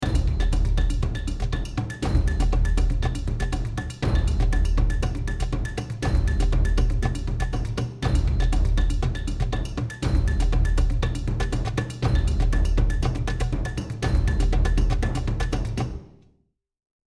Loop cut from MIDI files.